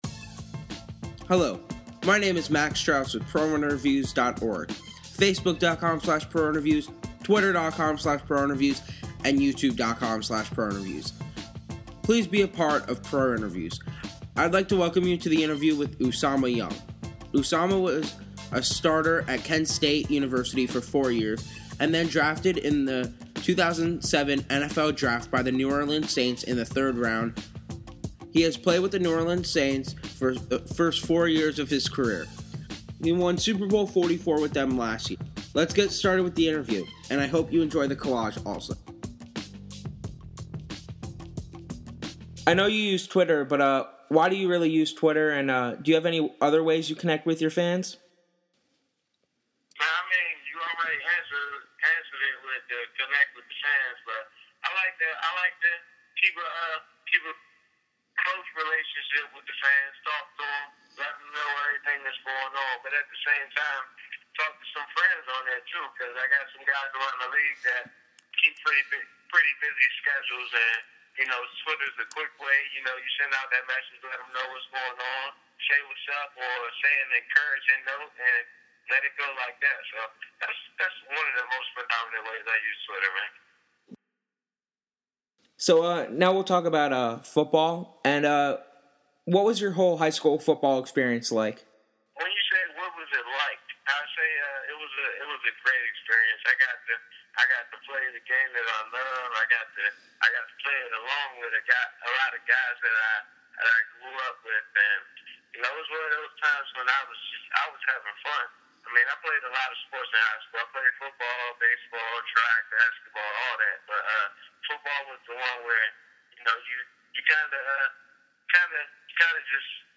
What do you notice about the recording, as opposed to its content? This interview was conducted briefly after the 2010 NFL season.